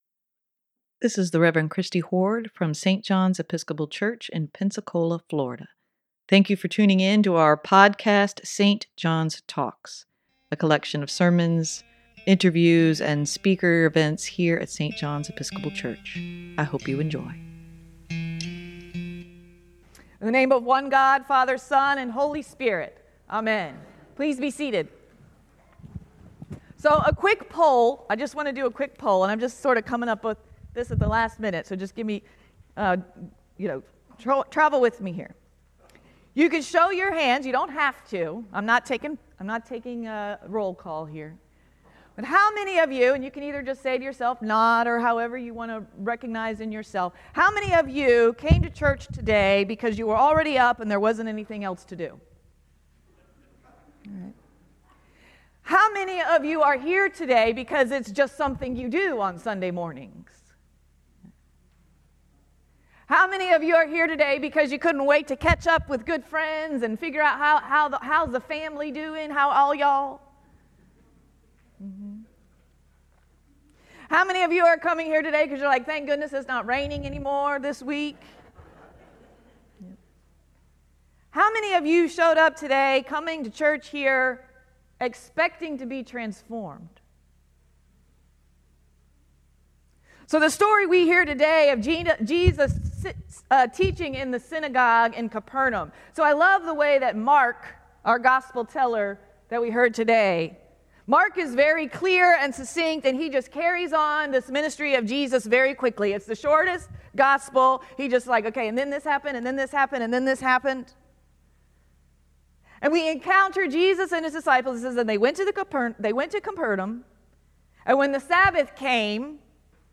Sermon for Jan. 28, 2024: Expect to be transformed